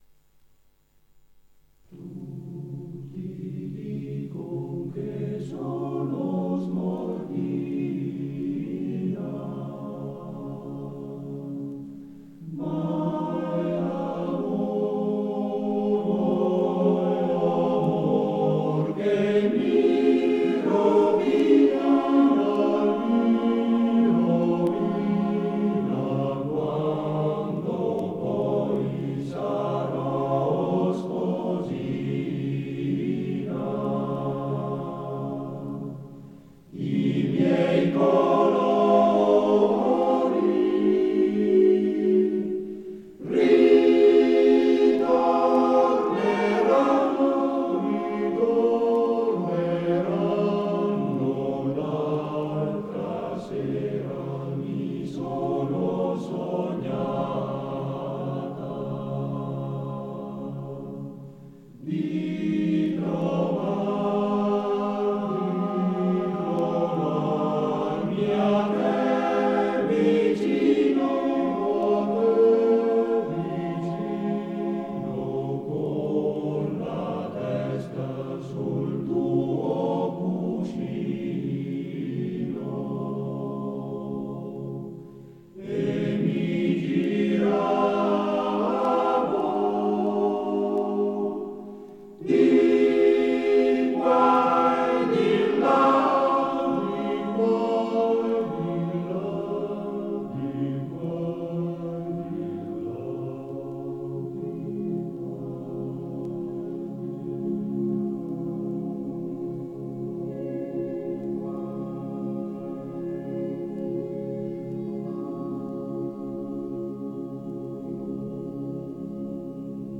Arrangiatore: Pigarelli, Luigi
Esecutore: Coro della SAT